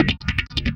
Pickup 01.wav